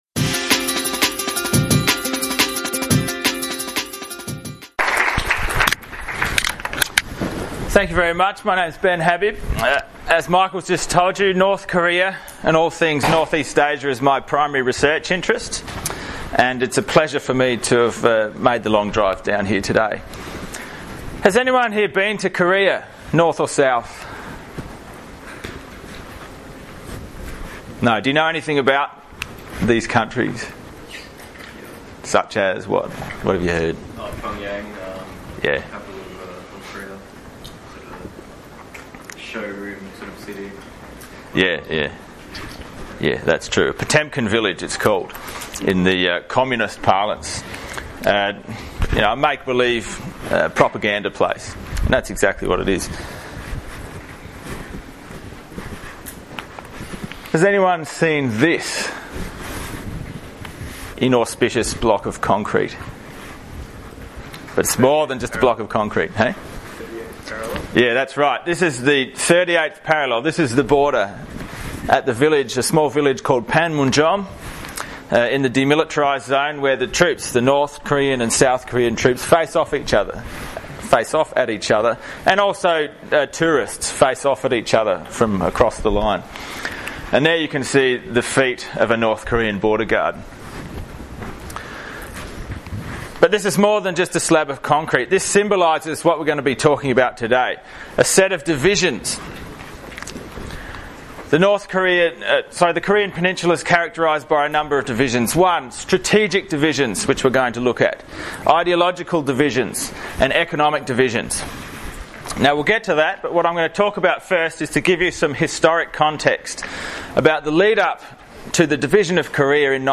DOWNLOAD: Presentation Audio (mp3) / PowerPoint Slides (pdf)